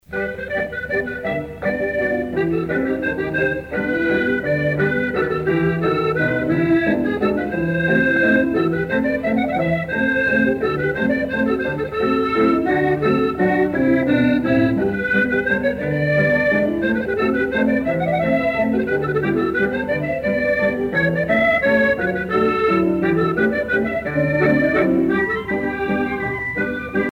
danse : java
Pièce musicale éditée